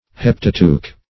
Meaning of heptateuch. heptateuch synonyms, pronunciation, spelling and more from Free Dictionary.
Search Result for " heptateuch" : The Collaborative International Dictionary of English v.0.48: Heptateuch \Hep"ta*teuch\, n. [L. heptateuchos, Gr.